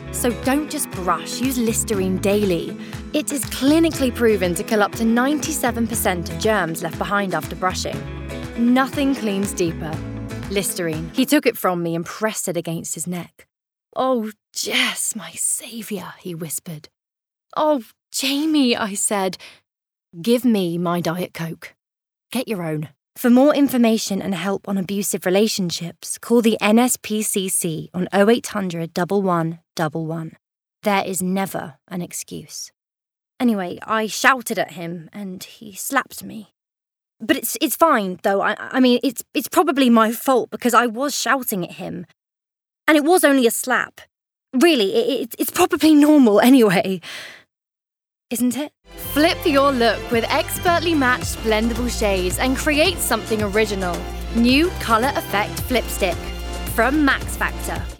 Female
Commercial Reel
RP ('Received Pronunciation')
Commercial, Bright, Fresh, Upbeat